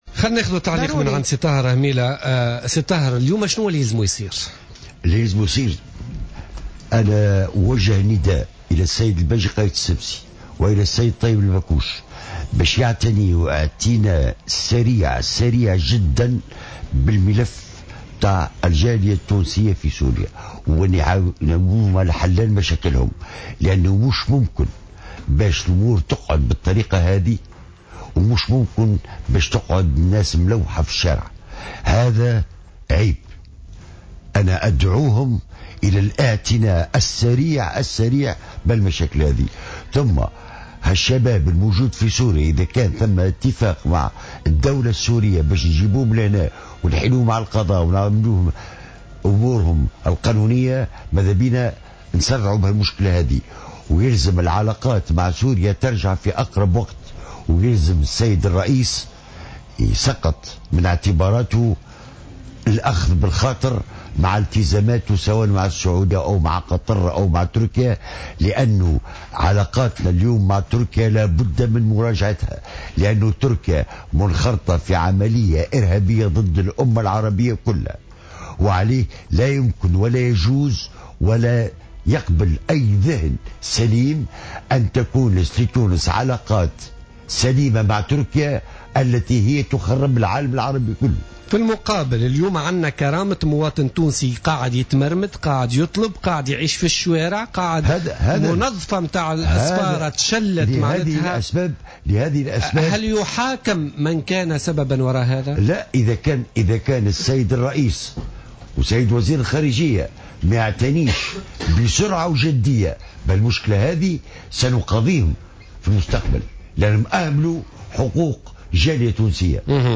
لوّح النائب السابق في المجلس الوطني التأسيسي الطاهر هميلة في برنامح "بوليتيكا" على "جوهرة أف أم" اليوم الاثنين 25 ماي 2015 باللجوء للقضاء في حال عدم تدخل رئيس الدولة ووزير الخارجية للإسراع بحلّ مشكلة التونسيين العالقين في سوريا.